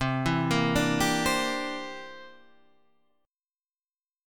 C 9th